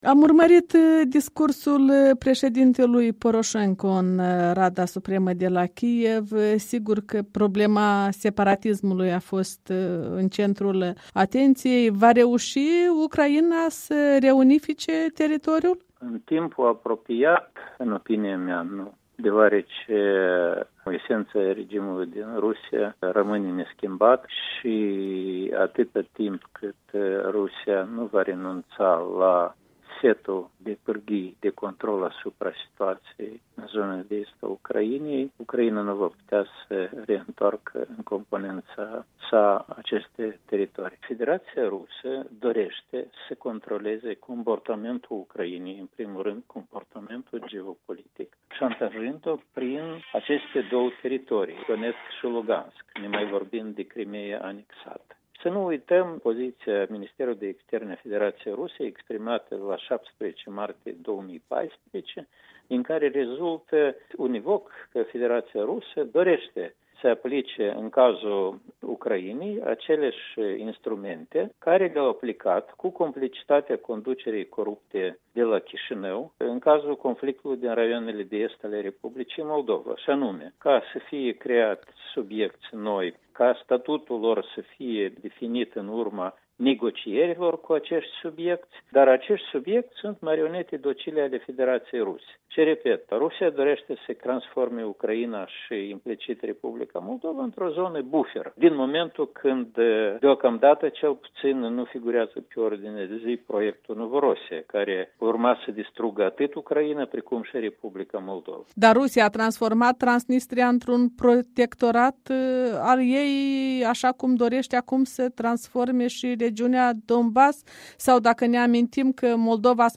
Un interviu cu directorul de programe al Institutului de Politici Publice.
Oazu Nanton (IPP) răspunde întrebărilor Europei Libere